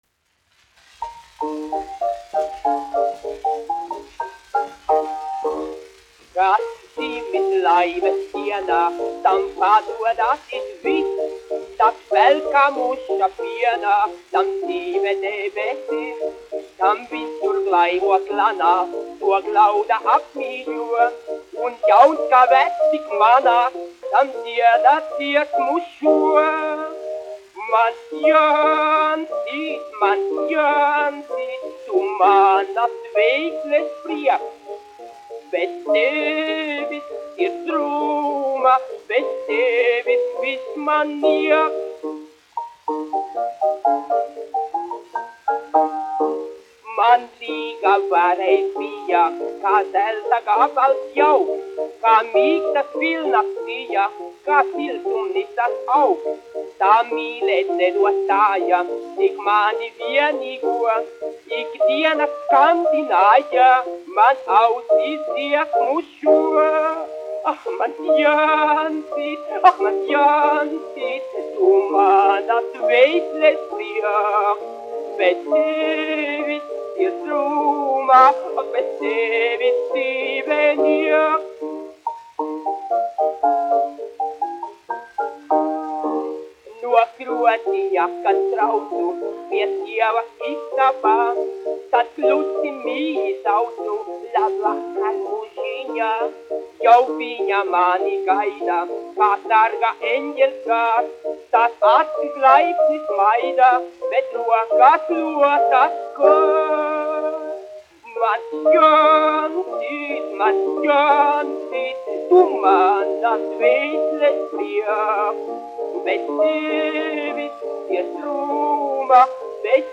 1 skpl. : analogs, 78 apgr/min, mono ; 25 cm
Populārā mūzika
Humoristiskās dziesmas
Skaņuplate
Latvijas vēsturiskie šellaka skaņuplašu ieraksti (Kolekcija)